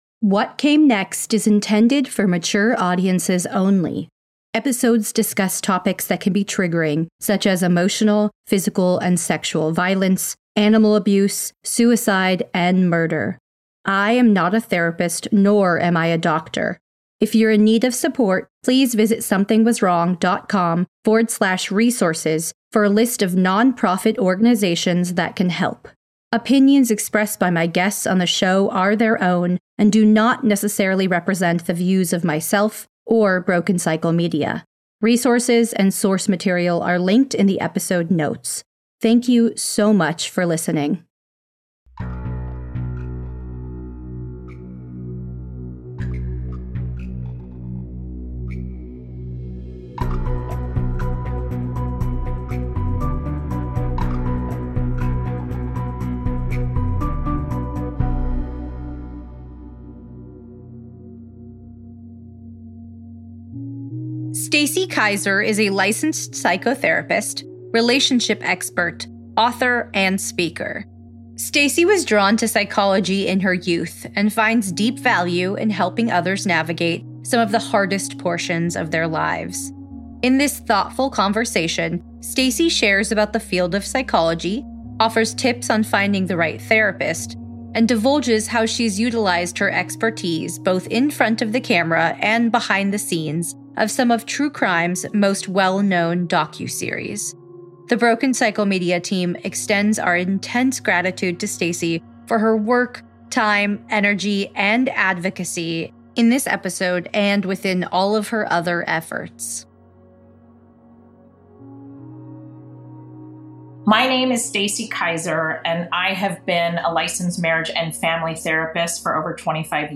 In this thoughtful conversation